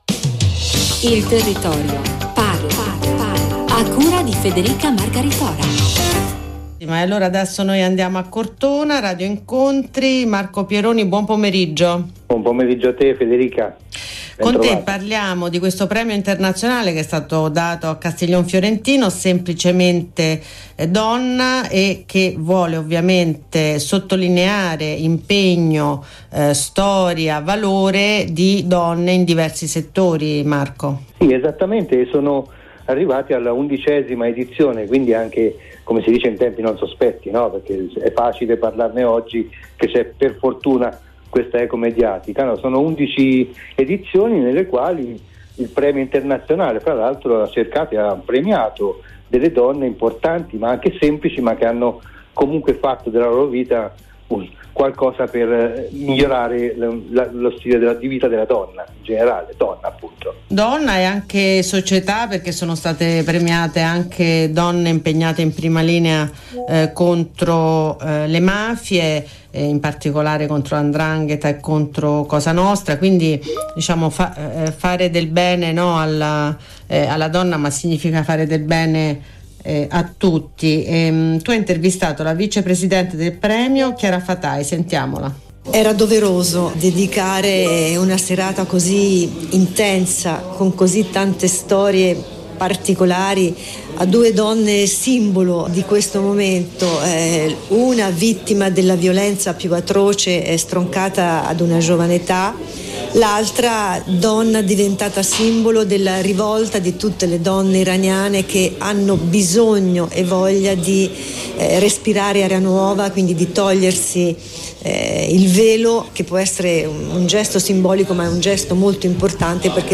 Uno speciale sull’intervista andata in onda su Radio Incontri inBlu e su inBlu2000 la radio Nazionale